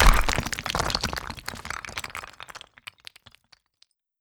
RockExplosion.wav